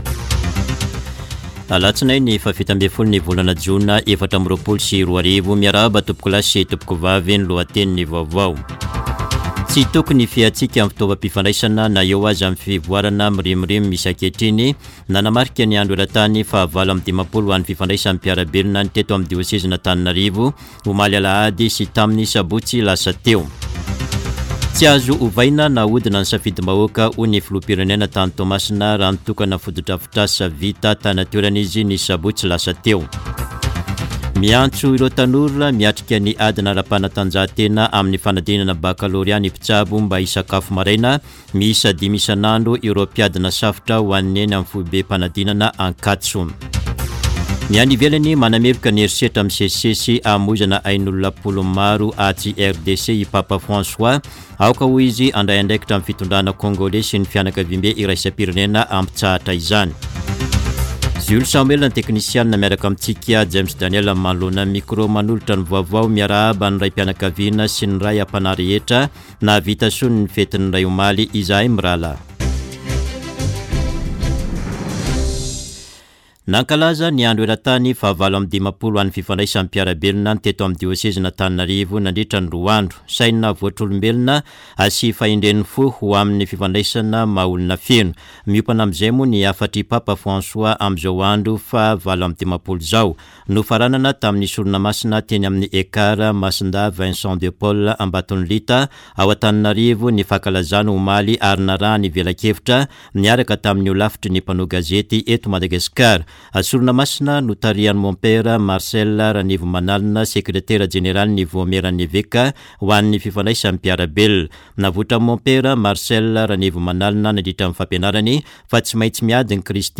[Vaovao maraina] Alatsinainy 17 jona 2024